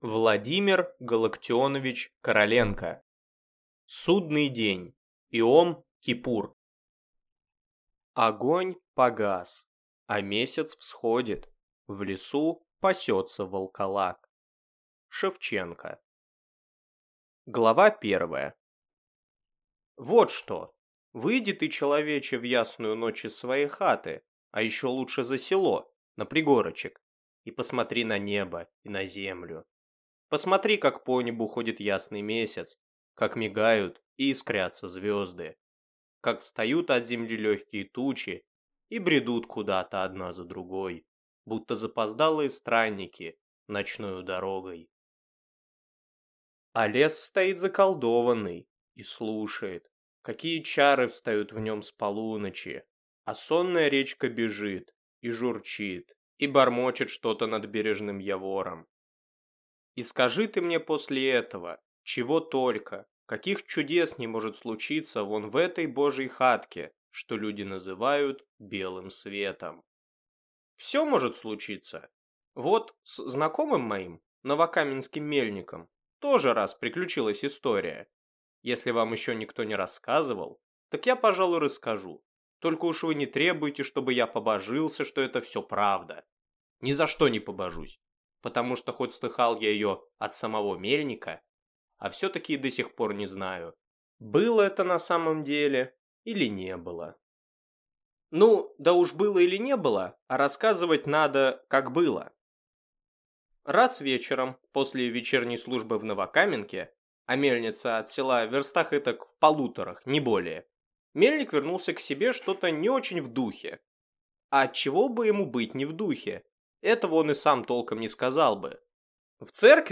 Аудиокнига Судный день («Иом-Кипур») | Библиотека аудиокниг